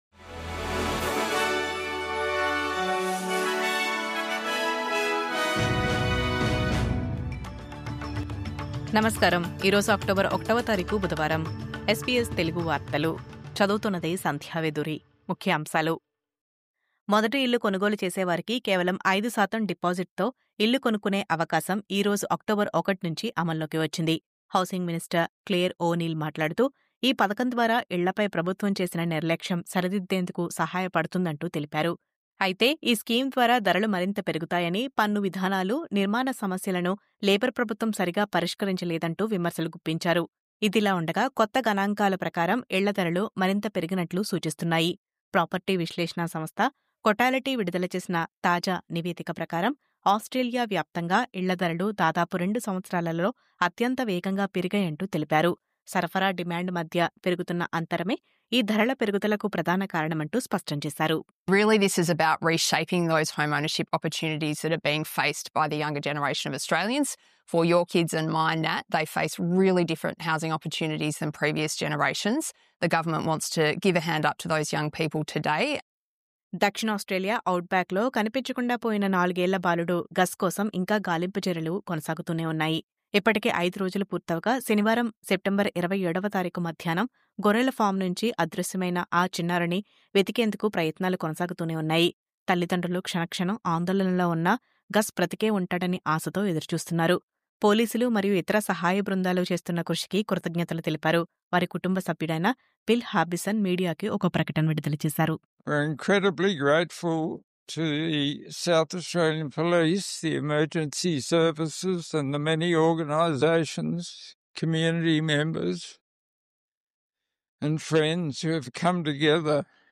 News update: ప్రయాణికుల సౌకర్యార్థం విదేశాల నుండి భారతదేశానికి వచ్చే వారికి e-Arrival Card..